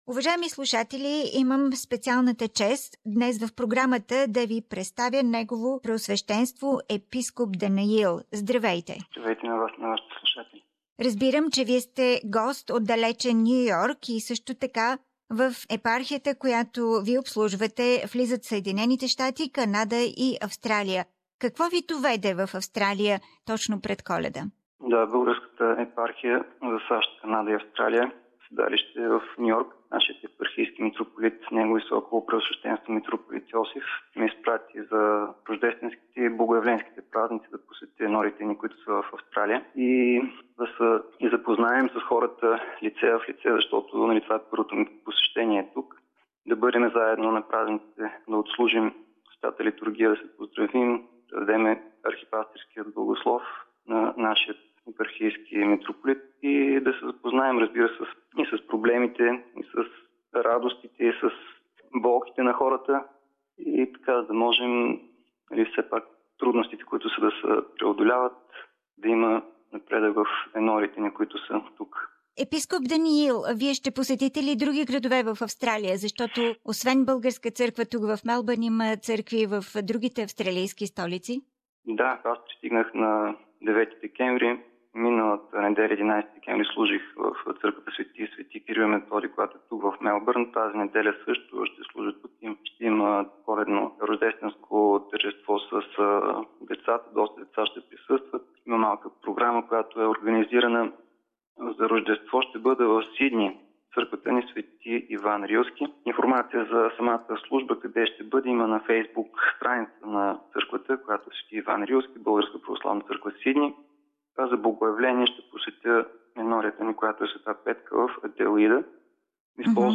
Interview and Christmas address by the visiting Vicar Bishop Daniil form the Bulgarian Orthodox Church